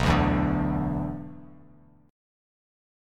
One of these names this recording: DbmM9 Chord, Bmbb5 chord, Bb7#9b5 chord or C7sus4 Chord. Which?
Bmbb5 chord